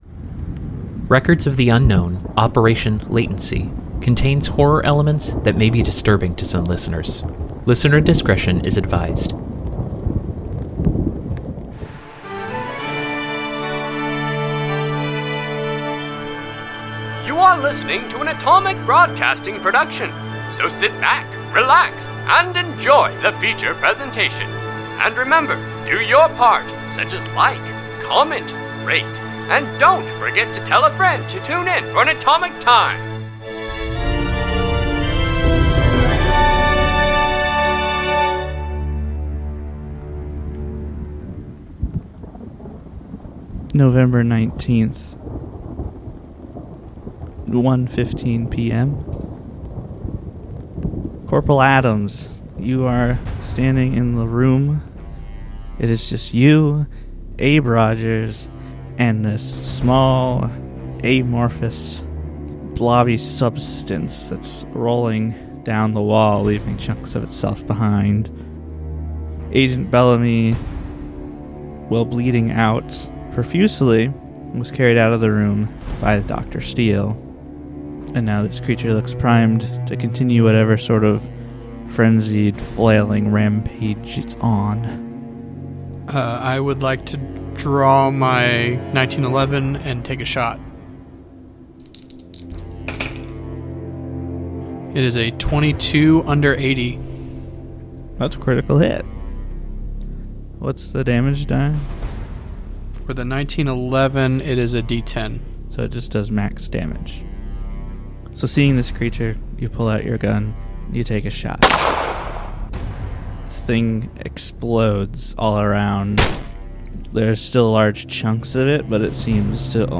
Records of the Unknown is an unscripted improvisational podcast based on the game Delta Green by Arc Dream